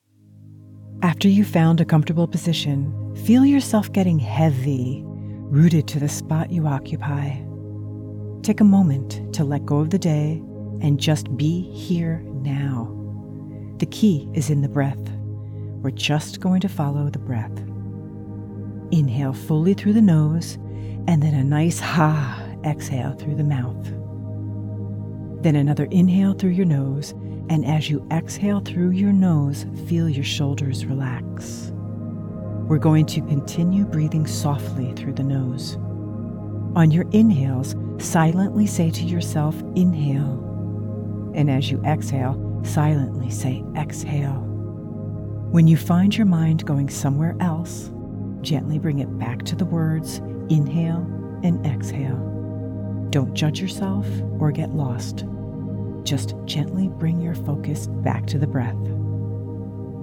mediation, calm, reassuring, inspiring
meditation demo_mixdown.mp3